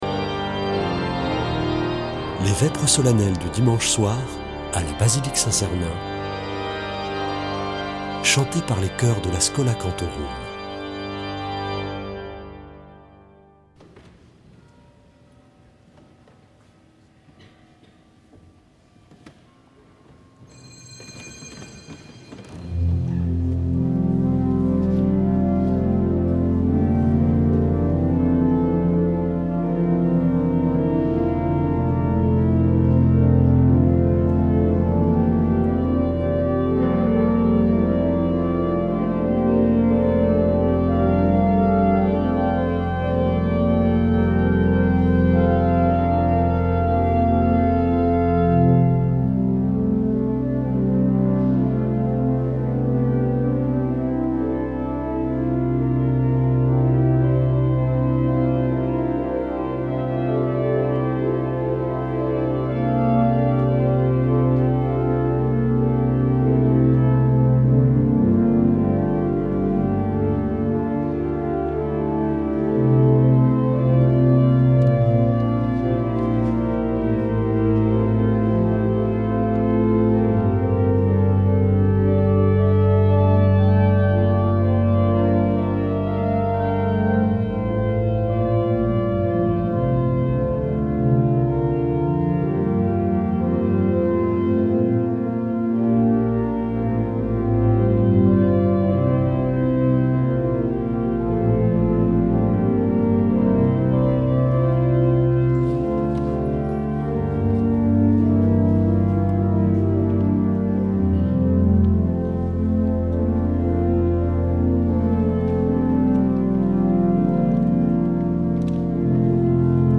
Vêpres de Saint Sernin du 10 déc.